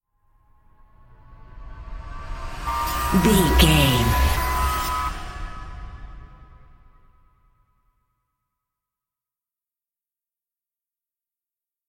Thriller
Aeolian/Minor
E♭
Slow
synthesiser
ominous
dark
suspense
haunting
creepy